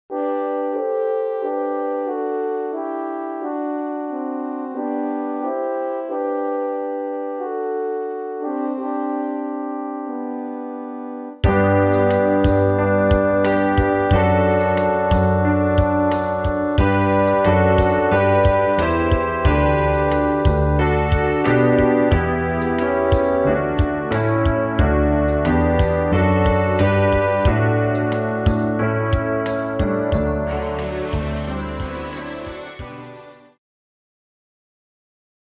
Christmas Karaoke Soundtrack
Backing Track without Vocals for your optimal performance.